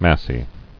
[mass·y]